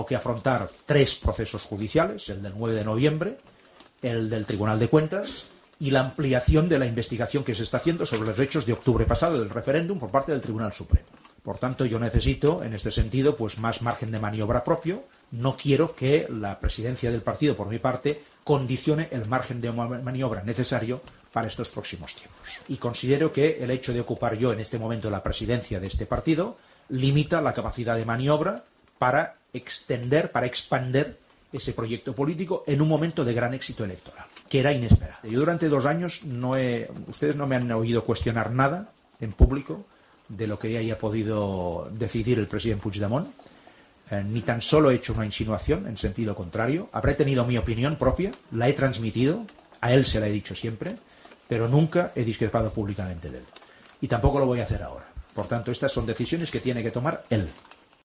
Mas ha anunciado su decisión en una rueda de prensa de urgencia en la sede del partido a menos de una semana de que se conozca la sentencia del caso Palau de la Música.